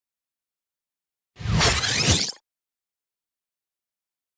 Âm thanh Chuyển tiếp Tua lại
Thể loại: Âm thanh chuyển cảnh
Description: Đây là âm thanh chuyển tiếp tua lại. Âm thanh phát ra khá ngắn khiến ta có chút khó khăn để cảm nhận, hình dung được đó là âm thanh như thế nào.
am-thanh-chuyen-tiep-tua-lai-www_tiengdong_com.mp3